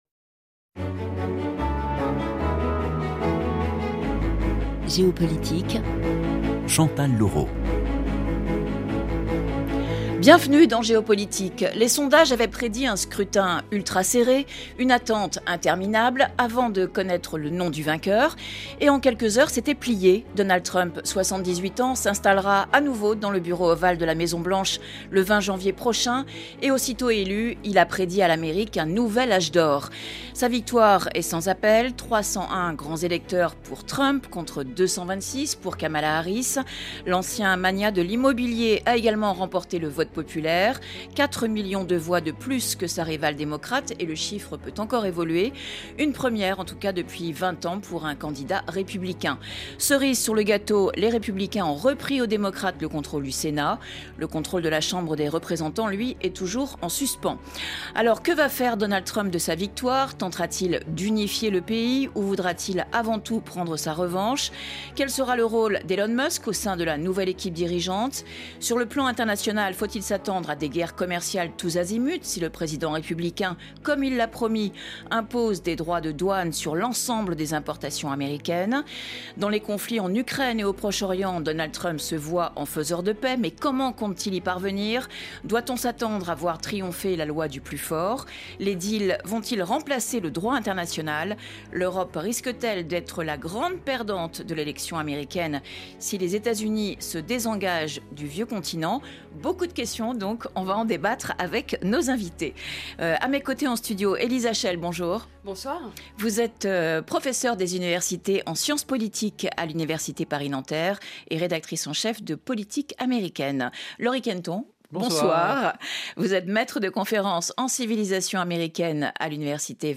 Les invités de Géopolitique confrontent leurs regards sur un sujet d’actualité internationale.